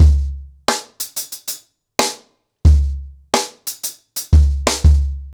CornerBoy-90BPM.23.wav